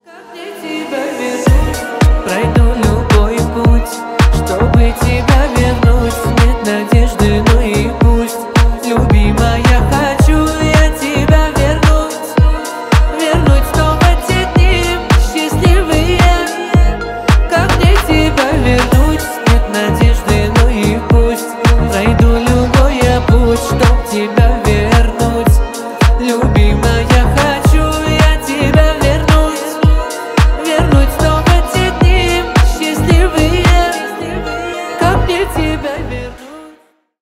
• Качество: 320 kbps, Stereo
грустные
кавказские